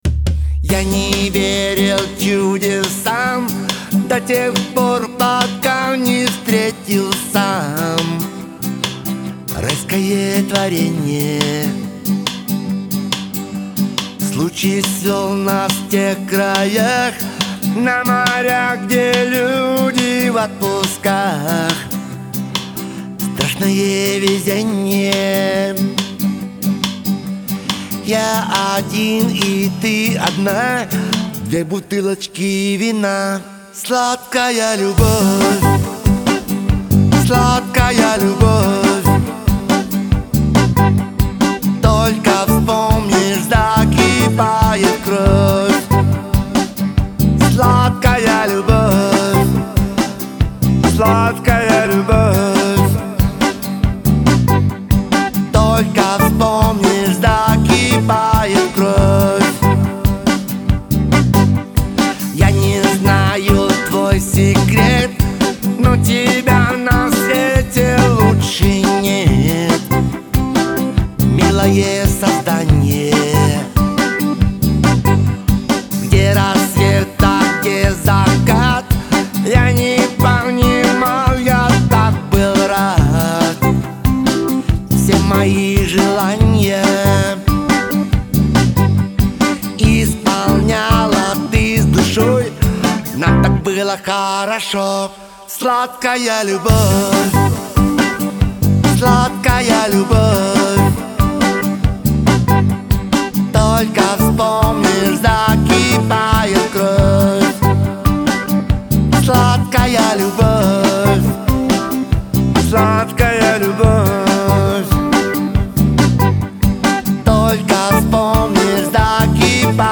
Жанры: рок-музыка, блюз, джаз, фьюжн,
поп-рок, новая волна, хард-рок, синтипоп